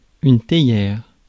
Prononciation
Synonymes thépot théquière thétière Prononciation France: IPA: /te.jɛʁ/ Le mot recherché trouvé avec ces langues de source: français Traduction Substantifs 1.